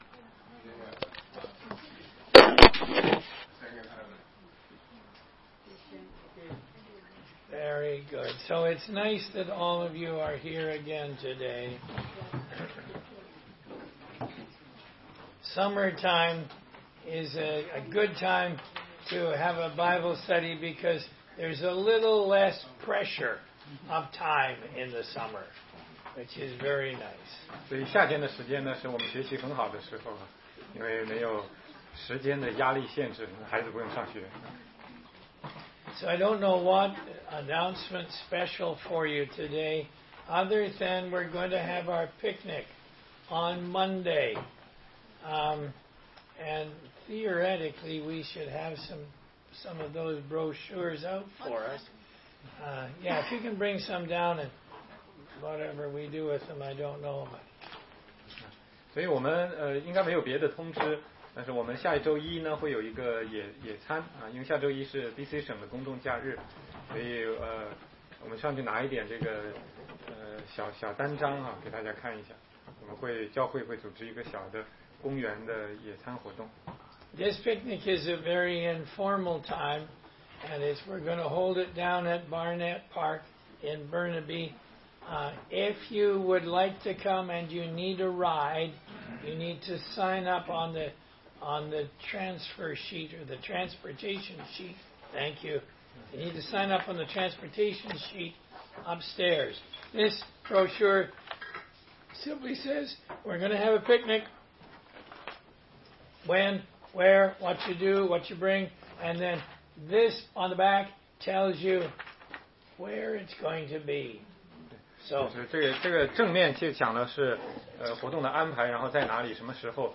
16街讲道录音 - 哥林多前书1章10-31节：止息教会的纷争要回到基督的十字架